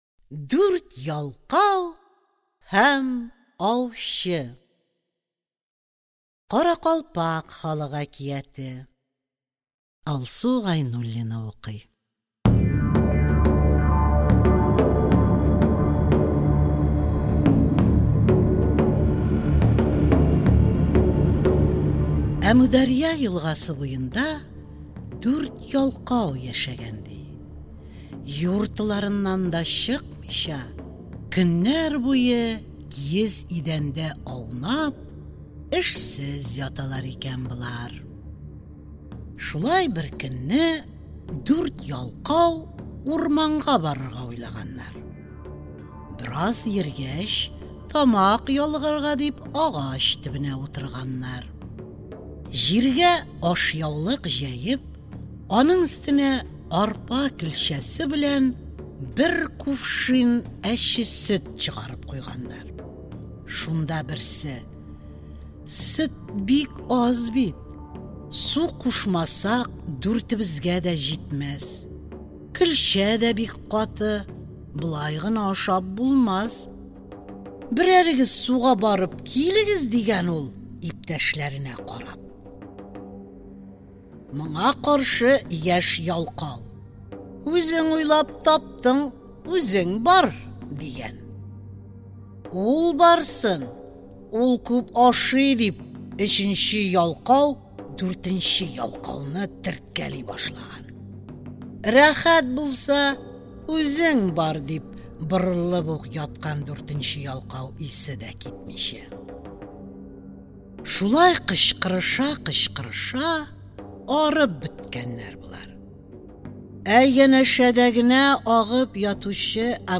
Аудиокнига Дөнья халыклары әкиятләре | Библиотека аудиокниг